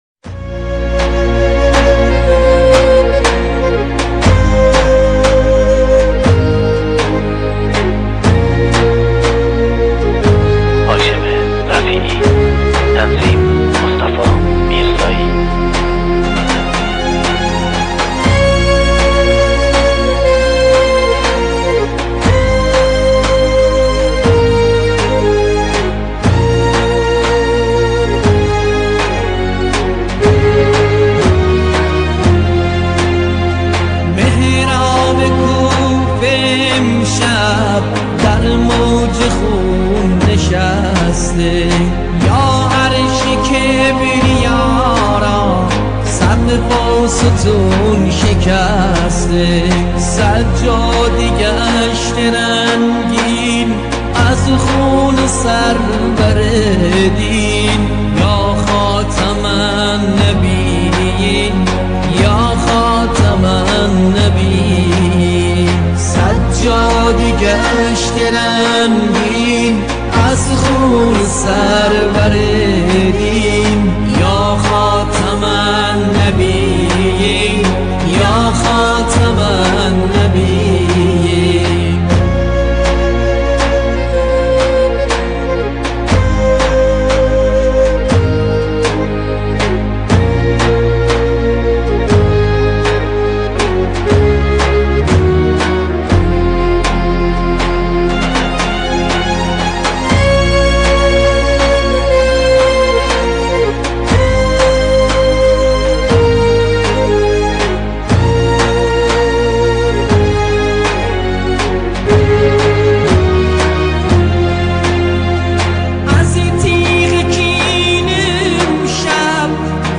آهنگ غمگین شهادت امام علی